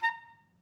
Clarinet
DCClar_stac_A#4_v1_rr2_sum.wav